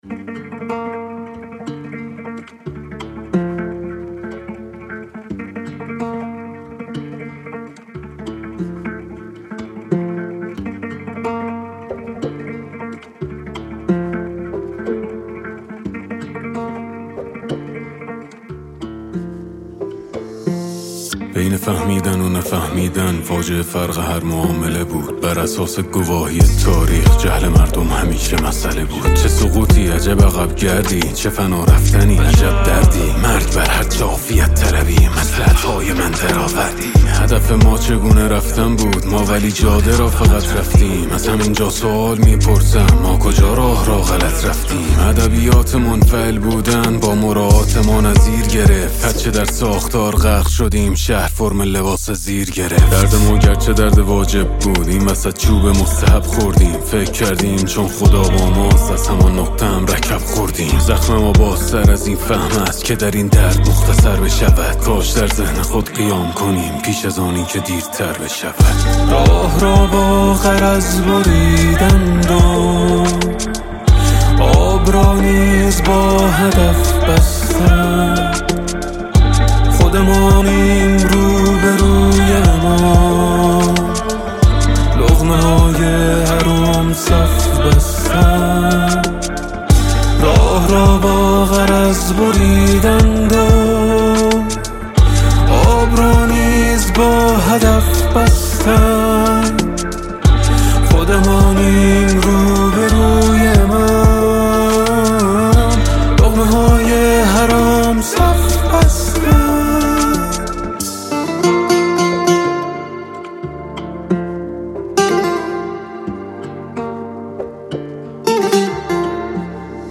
ژانر: آهنگ ، سرود انقلابی